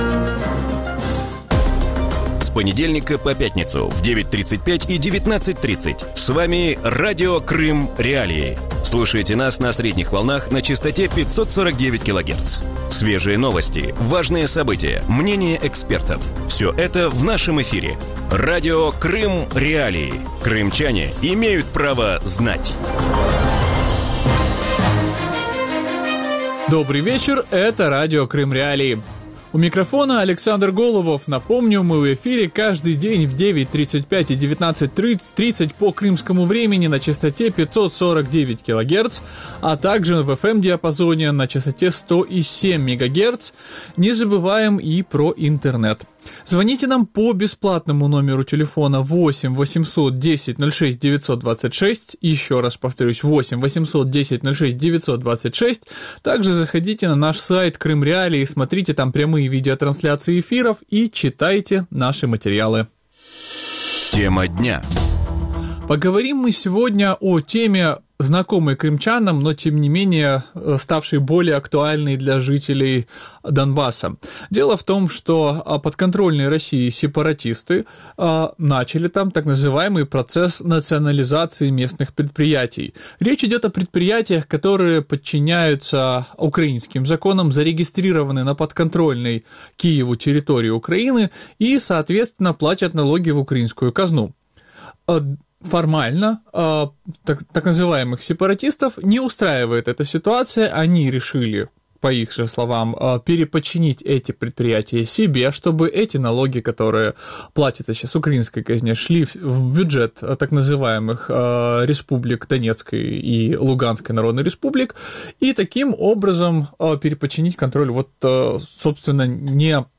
В вечернем эфире Радио Крым.Реалии говорят о волне экспроприации боевиками предприятий на неподконтрольной Украине территории. Что означает так называемая «национализация» украинского имущества, каким Кремль видит будущее временно оккупированных территорий на востоке Украины и ждет ли неподконтрольные Украине регионы Донбасса судьба Крыма?